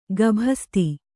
♪ gabhasti